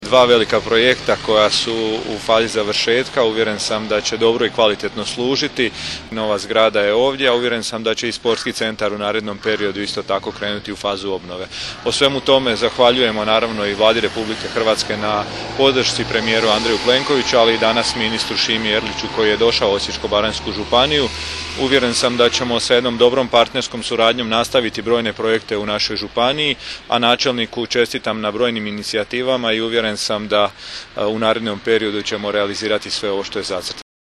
Zamjenik župana Osječko-baranjske županije, Josip Miletić, izrazio je uvjerenje kako će se dobra suradnja između lokalne vlasti, Ministarstva i Vlade Republike Hrvatske nastaviti.